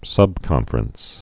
(sŭbkŏnfər-əns, -frəns)